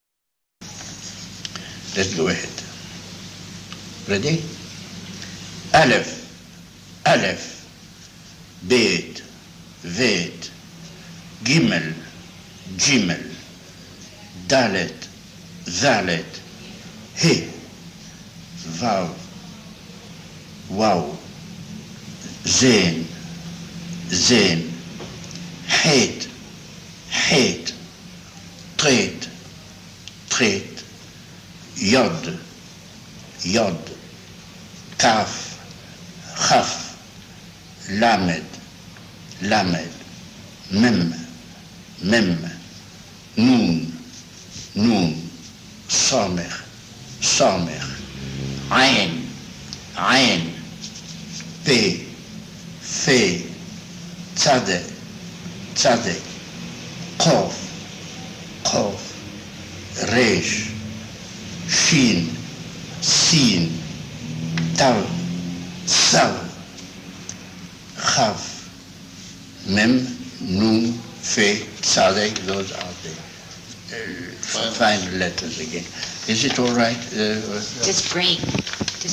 Carlo Suarès pronouncing the 22 Autiot
Suares_pronouncing-all-the-Autiot1973.mp3